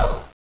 slide.wav